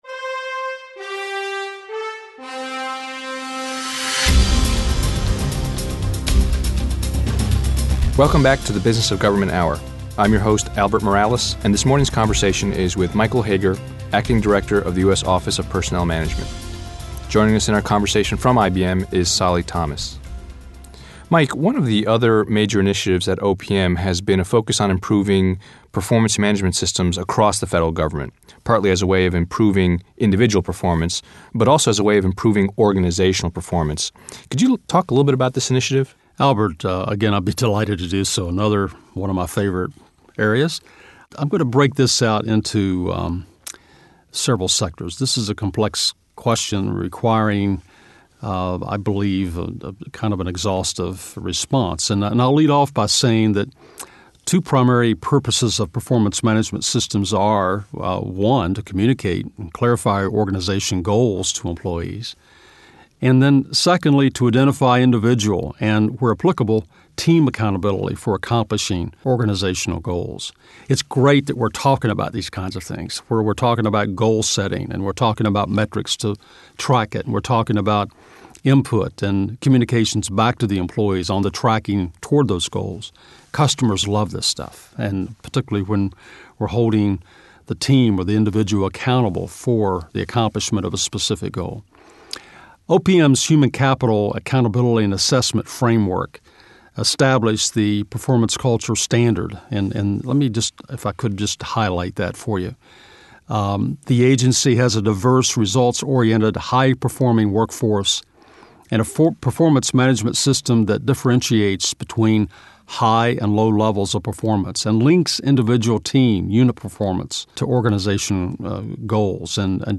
Interviews | IBM Center for The Business of Government
Michael Hager, Acting Director, Office of Personnel Management (OPM) Guest: Michael Hager